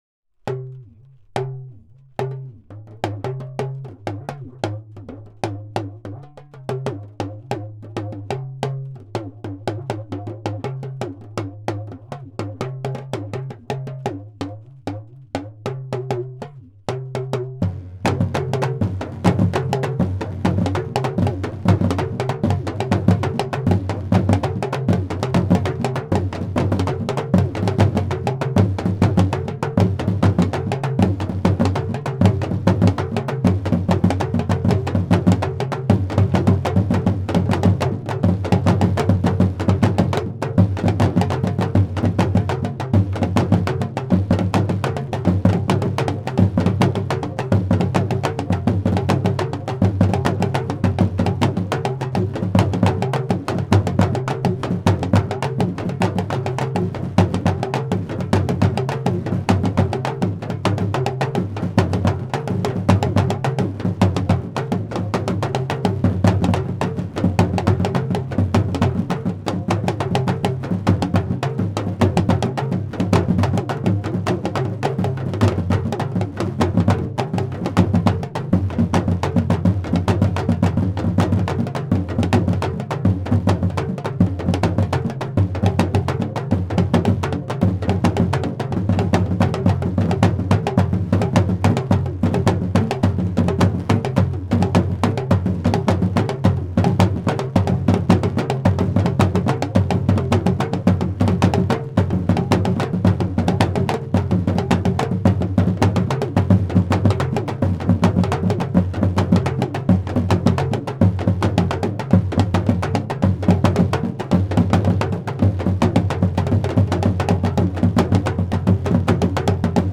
Damba Mangli drumming ensemble audio, multitrack aggregate
Talking drums Dagomba drumming
African drumming